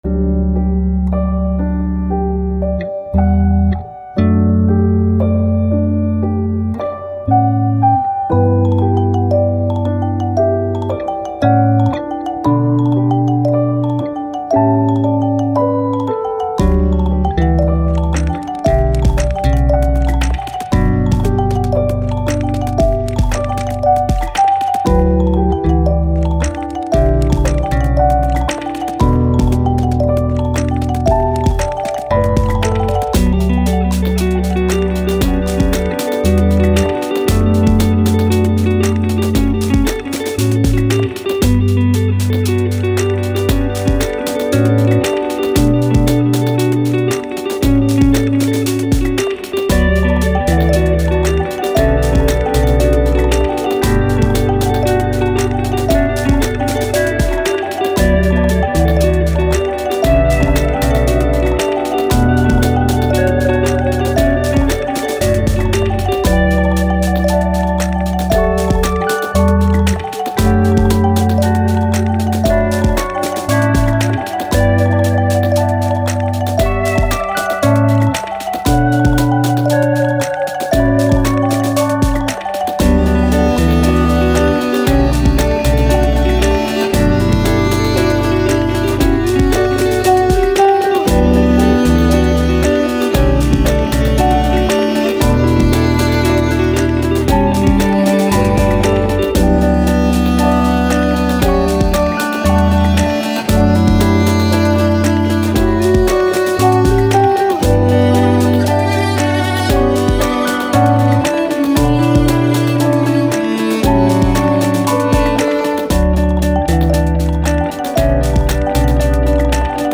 Ambient, Downtempo, Thoughtful, Landscapes